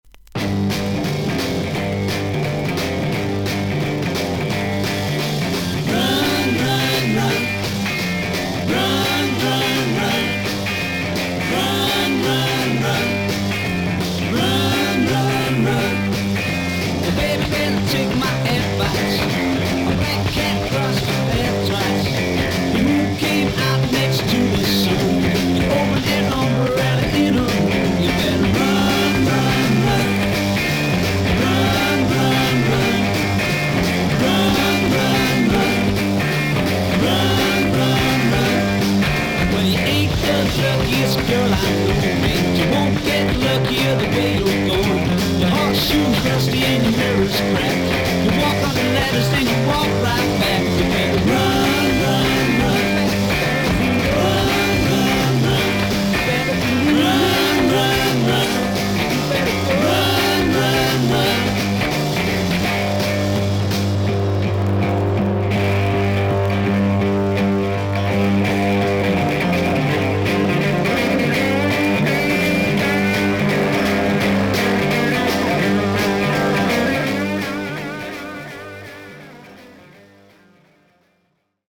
盤面に長短のキズが見えますが、それほど音に出ません。
少々サーフィス・ノイズあり。クリアな音です。